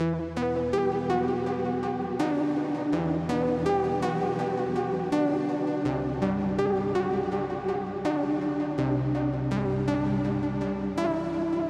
Ранее все было прекрасно, но теперь эта партия играет... скажем так с огрехами по модуляции... Фальш конкретная! Еще и вибрато с хорусом...